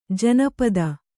♪ janapada